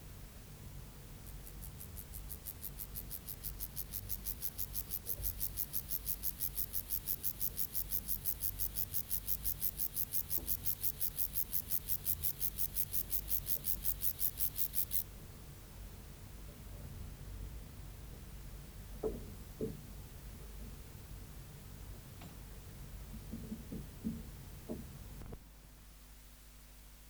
C_vagans.wav